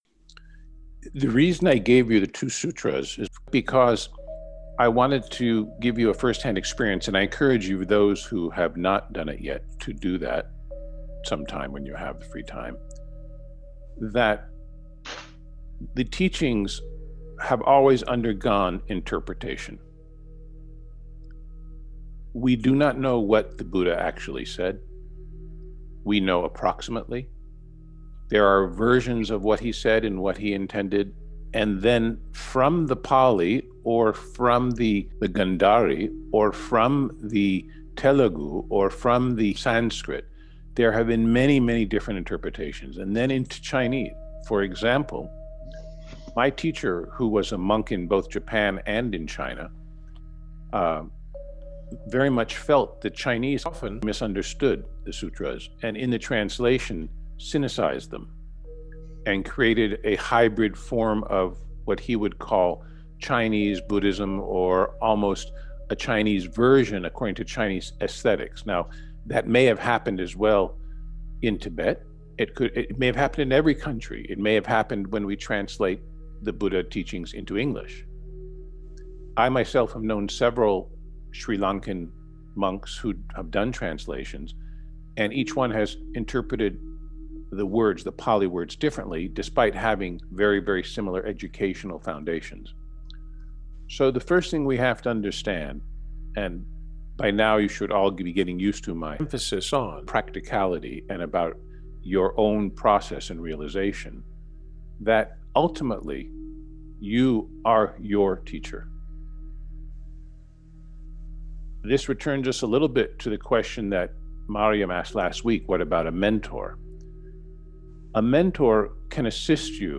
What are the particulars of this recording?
We will use the Mahasaccaka Sutta as a jumping off point for our discussion. Here is an excerpt of our 1.5 hour long discourse.